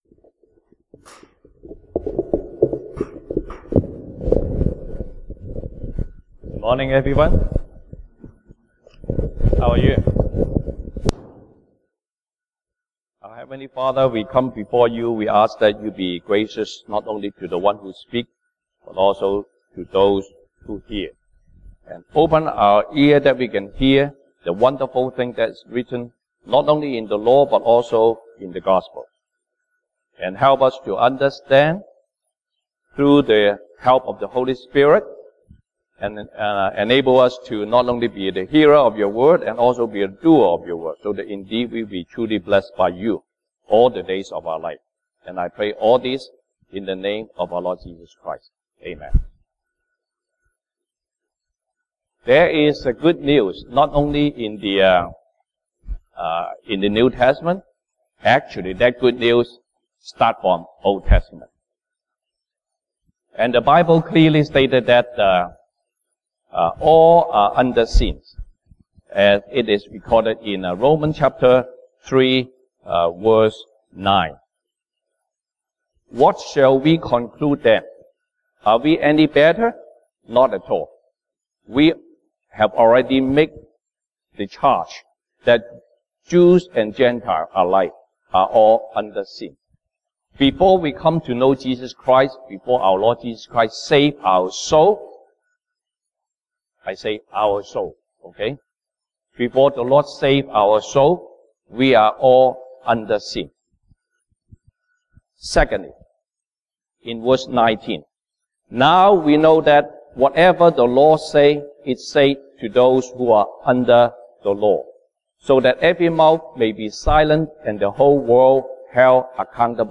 Sunday Service English